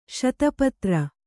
♪ śata patra